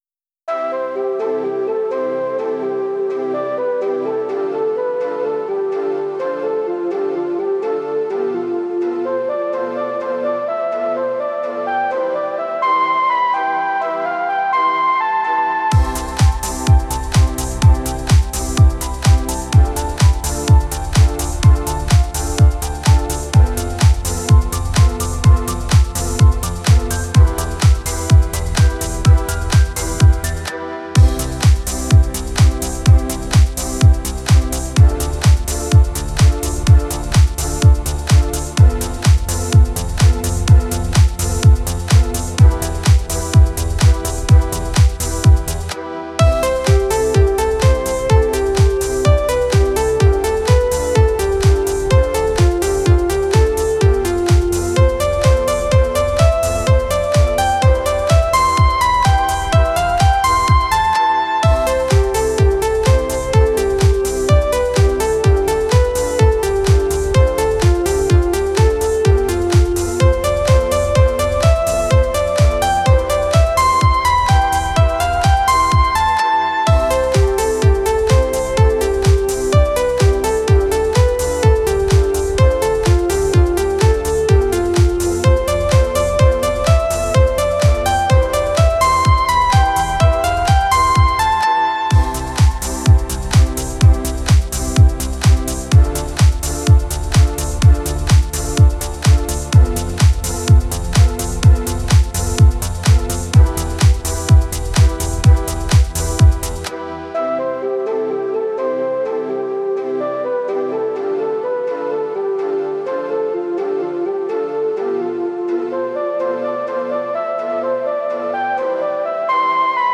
ELECTRO G-N (38)